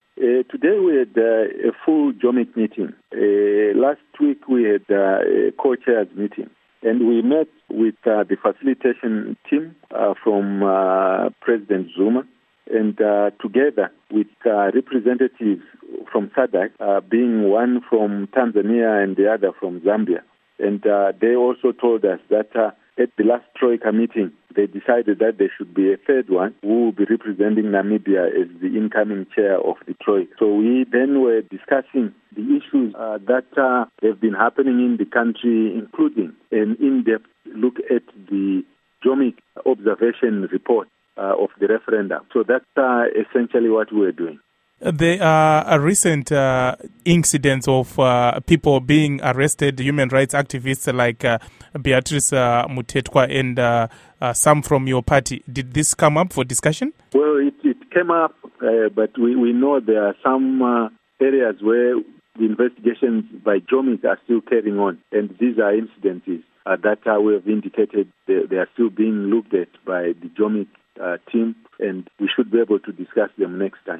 Interview With Elton Mangoma